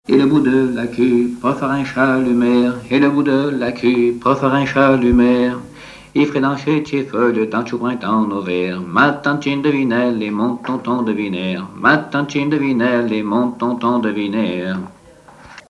Instrument(s) de musique ; instrument(s) buissonnier(s)
Genre laisse
Pièce musicale inédite